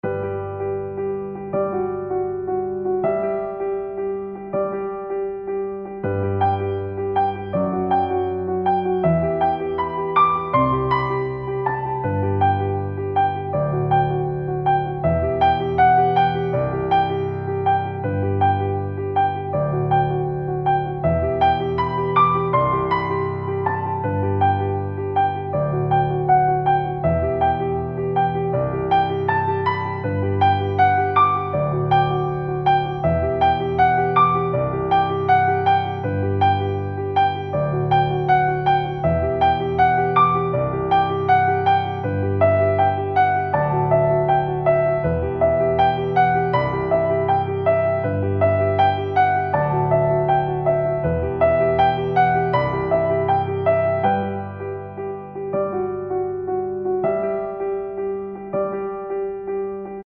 Piano-Loop.mp3
KGvpM6cHDB4_Piano-Loop.mp3